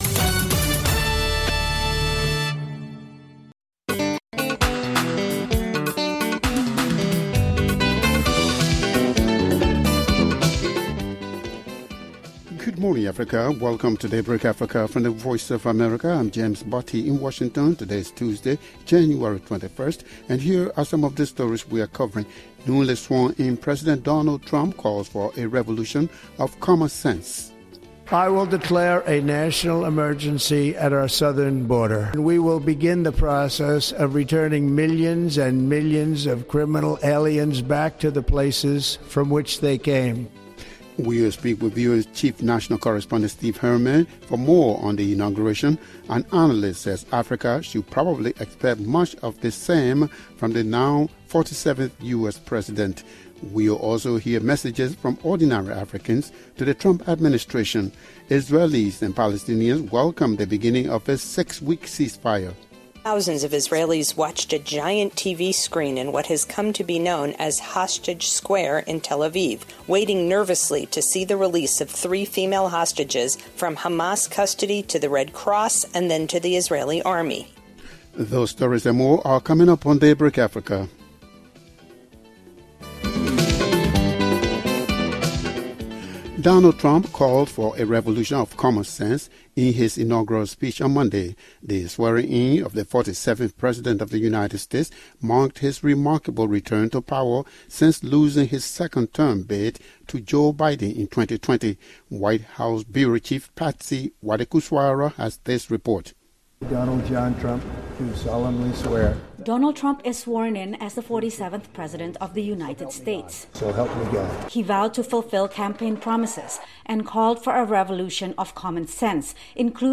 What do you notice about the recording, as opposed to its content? An analyst says Africa should probably expect much of the same from the now 47th US president. We’ll also hear messages from ordinary Africans to the Trump administration. Israelis and Palestinians welcome the beginning of a six-week ceasefire.